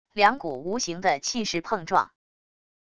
两股无形的气势碰撞wav音频